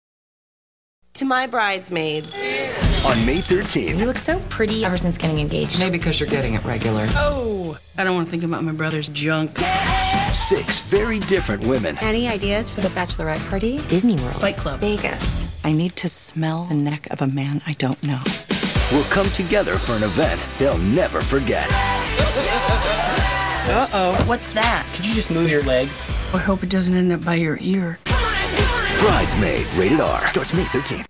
Brides Maids TV Spots